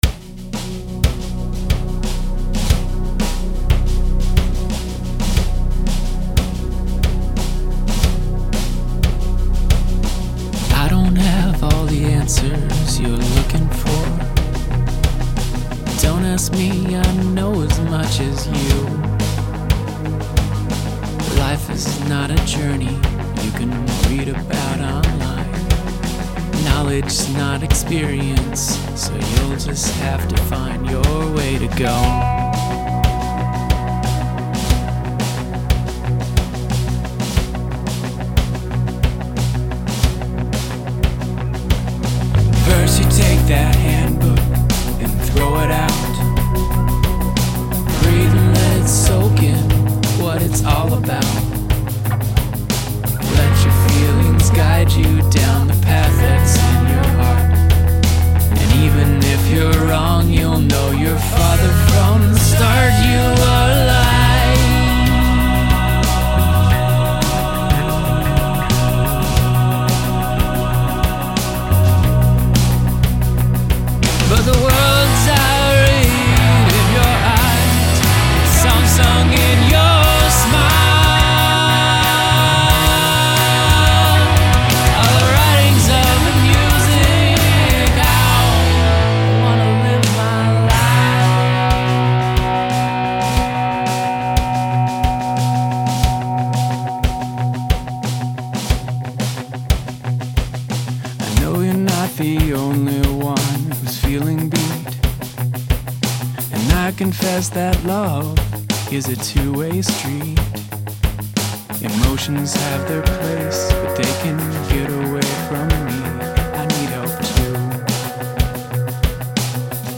love these drums, Pearl jam vibes